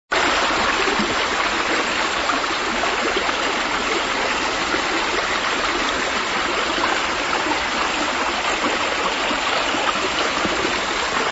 6 – نغمة صوت خرير ماء النهر